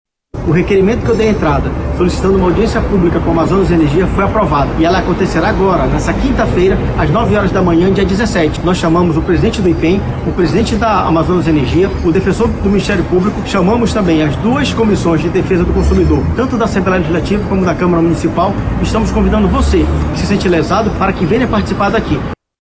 Sonora-Jander-Lobato-vereador.mp3